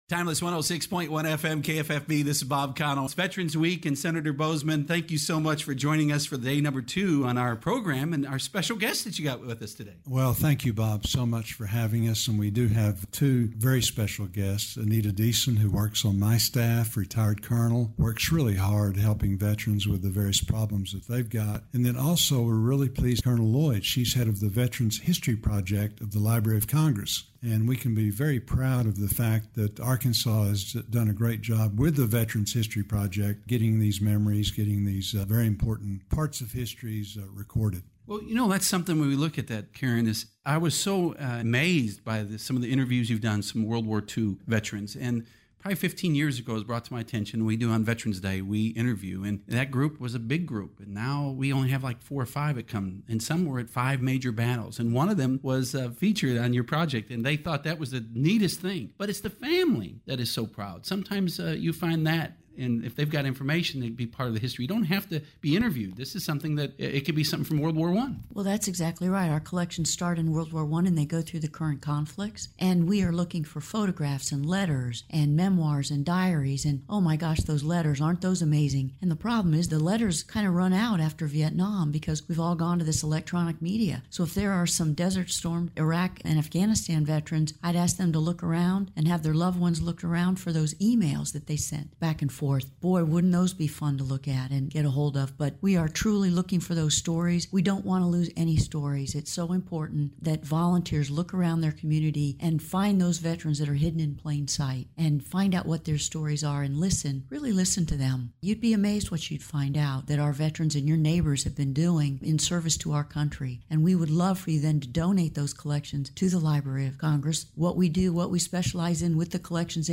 U.S. Senator John Boozman (R-AR) on Timeless 106.1 KFFB’s Open Mic Day #2 Tuesday, November 12, 2019, for Veterans Week, November 11th-15th.
KFFBs-Open-Mic-with-Senator-John-Boozman-Day-2-2019.mp3